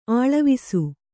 ♪ aḷavisu